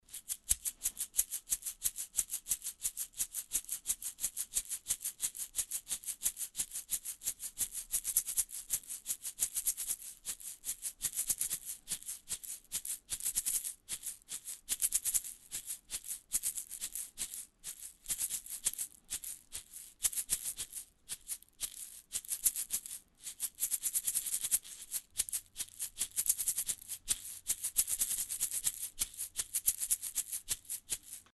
• seed shaker - egg shaker.mp3
Recorded with a Steinberg Sterling Audio ST66 Tube, in a small apartment studio.
seed_shaker_-_egg_shaker_j2d.wav